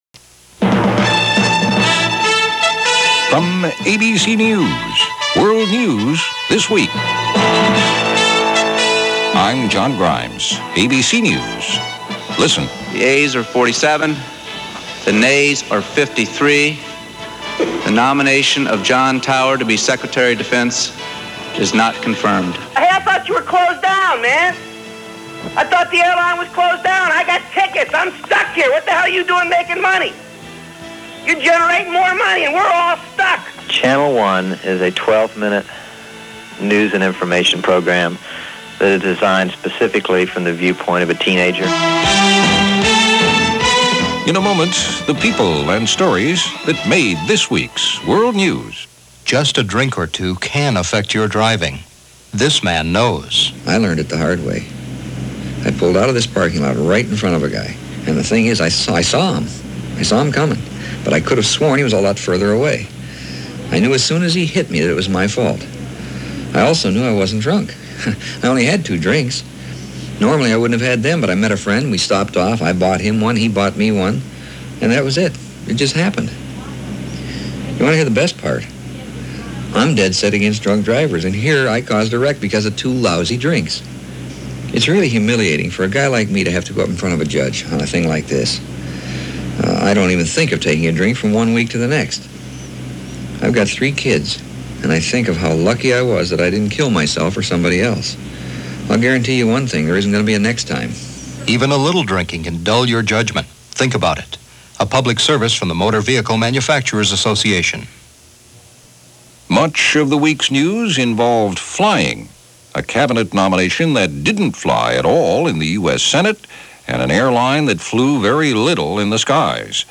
ABC Radio